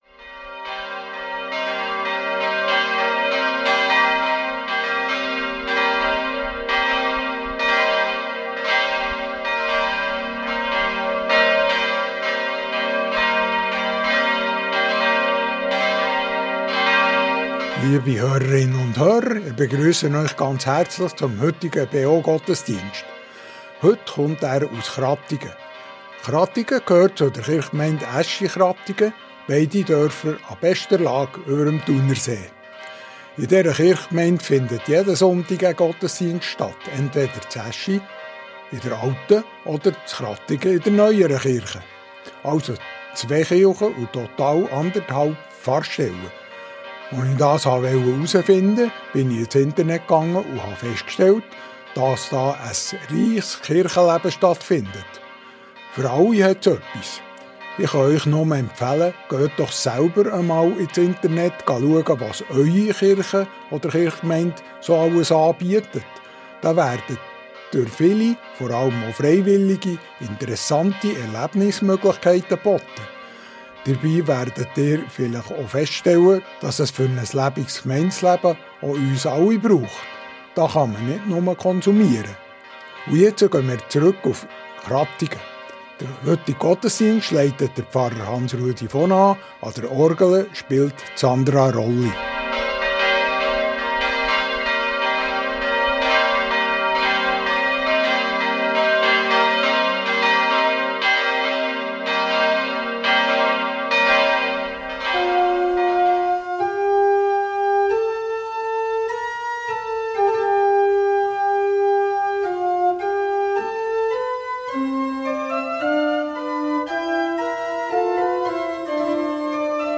Kirche Krattigen der reformierten Kirchgemeinde Aeschi-Krattigen ~ Gottesdienst auf Radio BeO Podcast